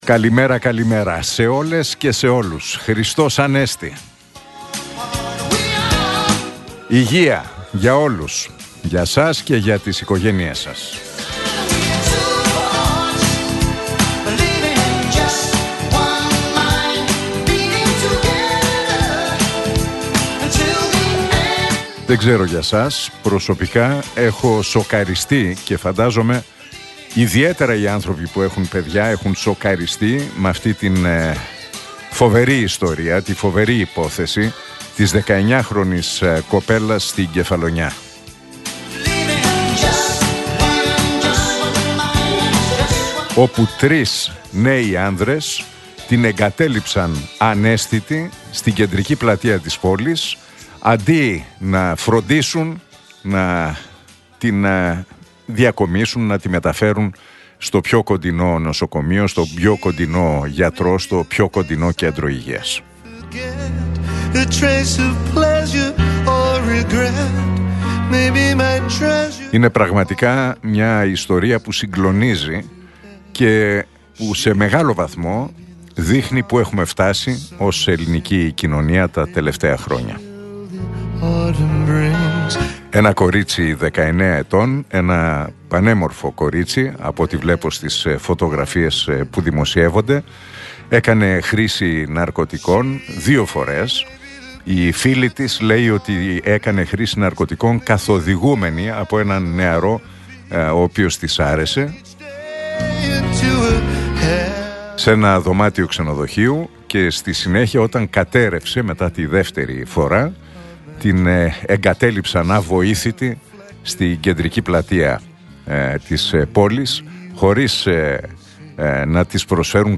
Ακούστε το σχόλιο του Νίκου Χατζηνικολάου στον ραδιοφωνικό σταθμό Realfm 97,8, την Τετάρτη 15 Απριλίου 2026.